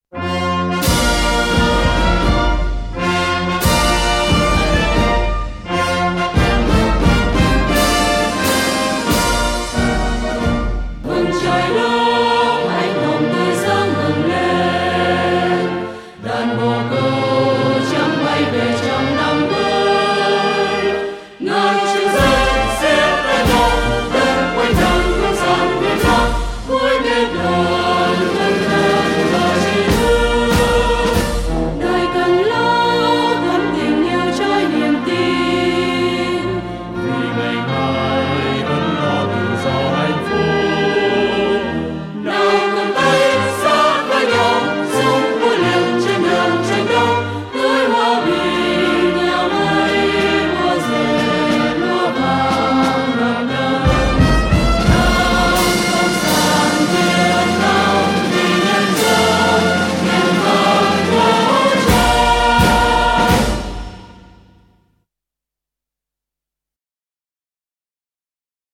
File nhạc có lời